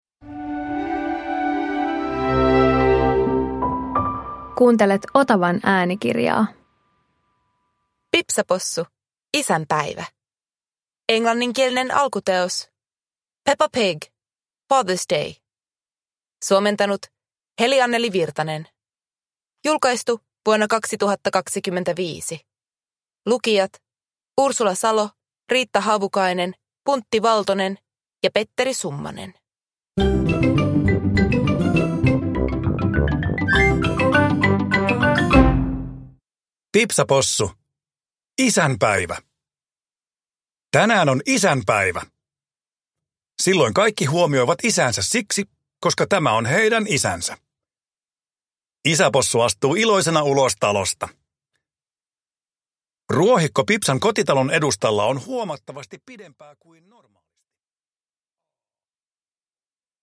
Pipsa Possu - Isänpäivä (ljudbok) av Various